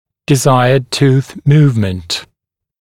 [dɪ’zaɪəd tuːθ ‘muːvmənt][ди’зайэд ту:с ‘му:вмэнт]желаемое перемещение зуба